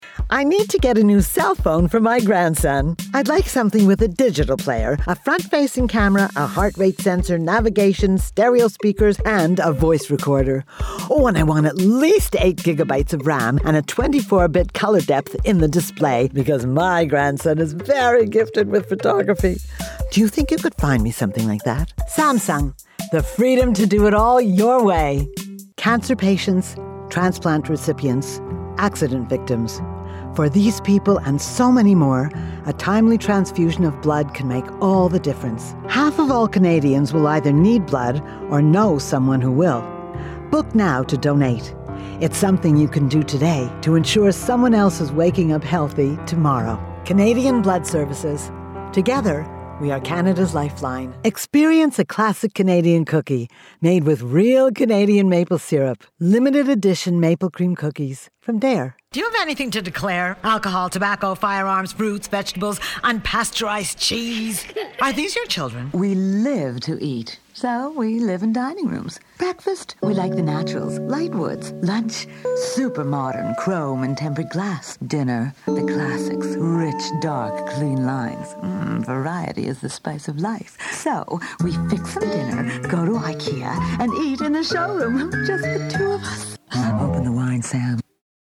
Voice demo - EN